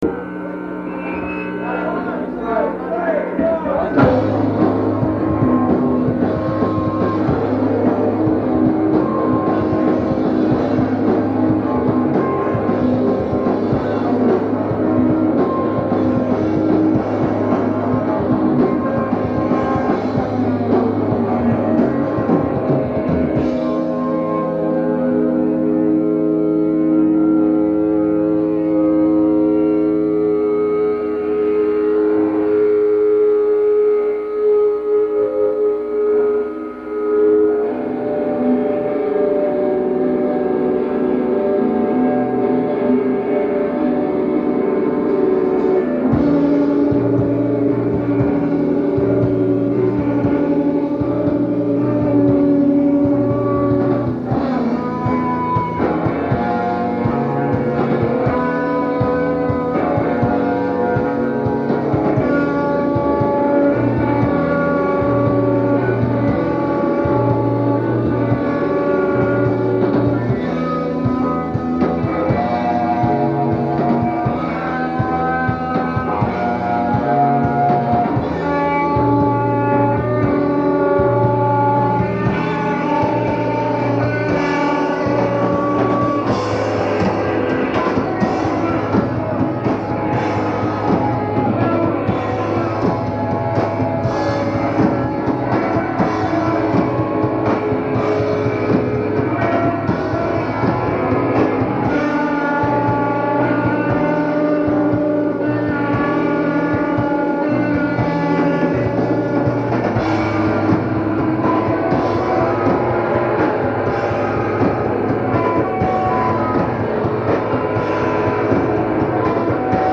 noise-rock
singer/bassist